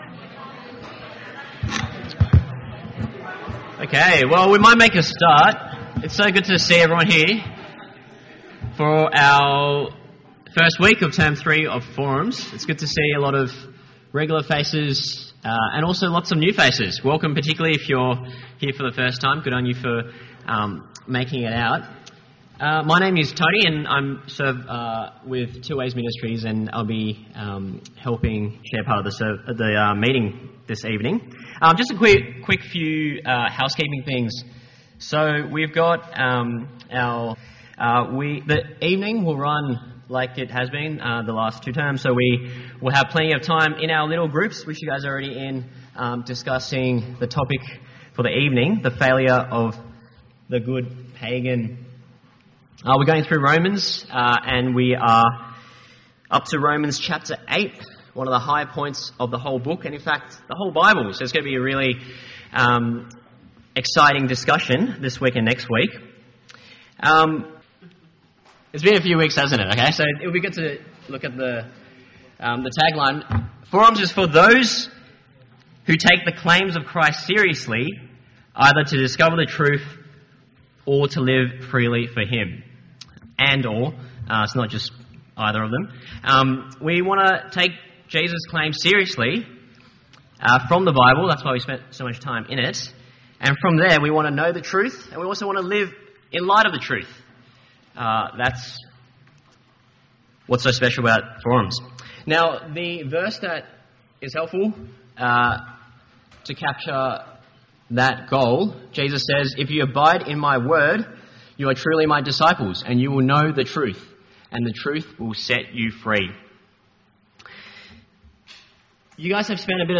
An edited audio version of Week 1, Term 3 of Forums 2024. Forum is a 2 hour session of interactive Bible teaching for 18-30 year olds.